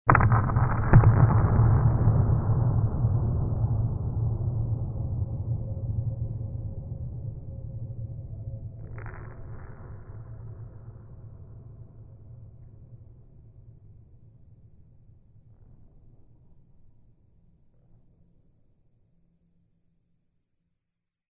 Zvuk-kinematograficheskogo-atomnogo-vzryva267.65 kB96kB6